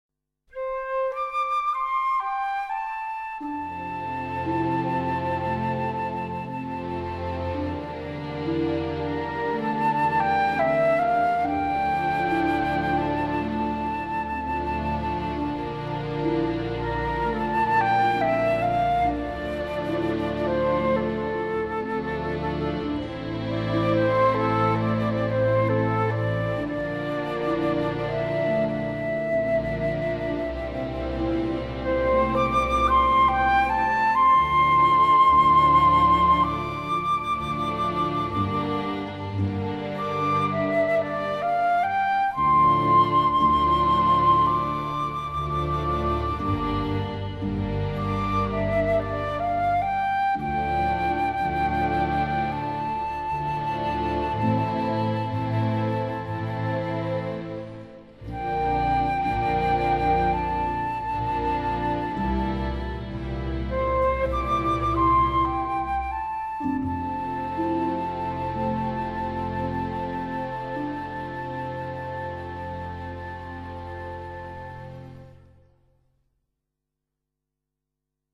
这一旋律具有一种典雅高贵的品质，节奏舒缓流畅、旋律优美动人